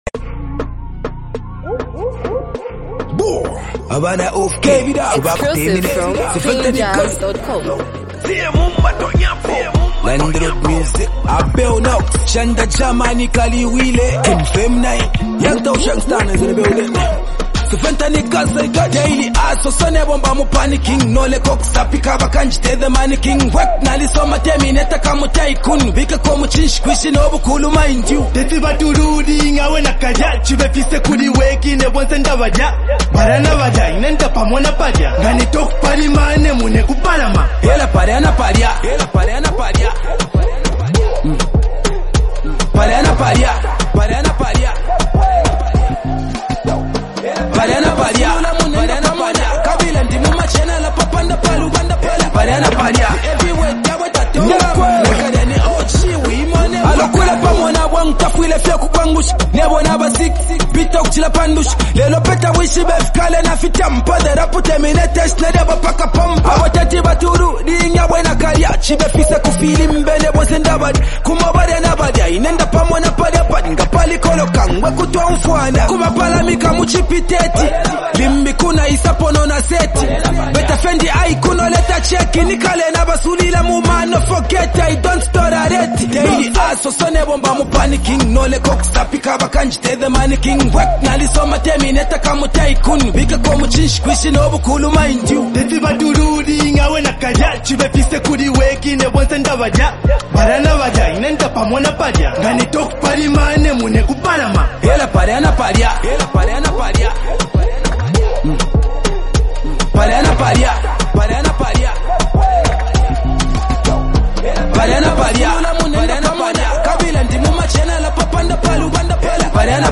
reflective street anthem that blends melody with raw emotion
soulful and catchy hook